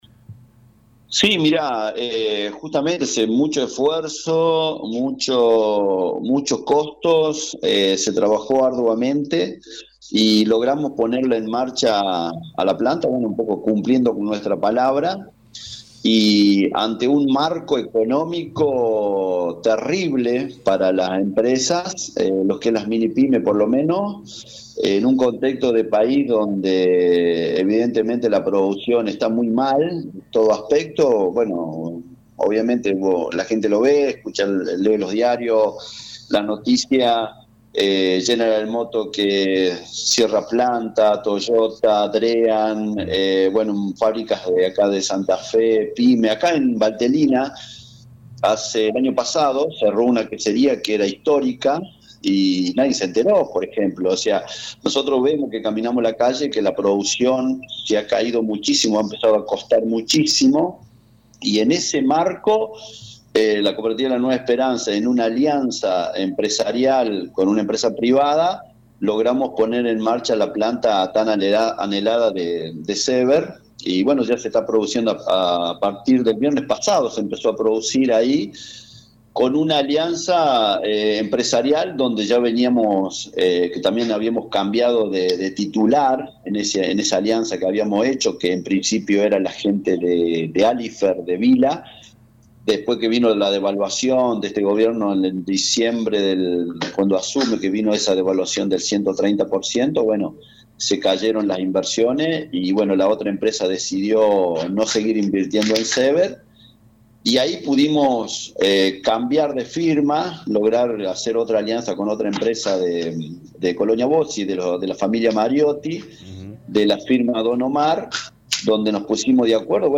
Coop. La Nueva Esperanza después de mucho tiempo de inversiones en la planta Seeber cumple su palabra de la puesta en marcha de esa planta productiva. ENTREVISTA